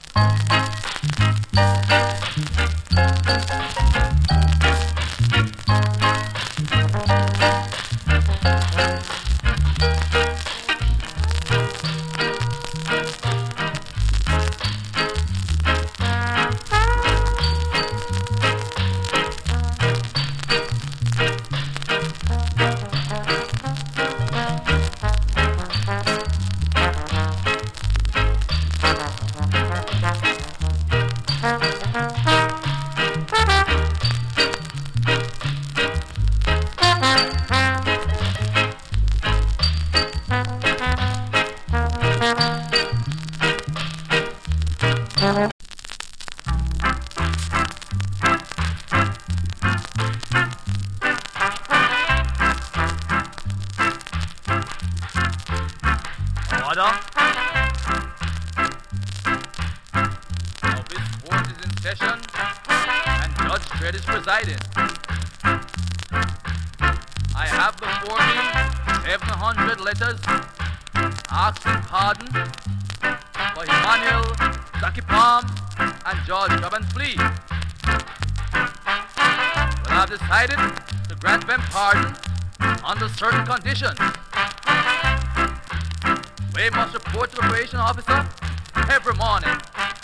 チリ、パチノイズ多数有り。
INST CUT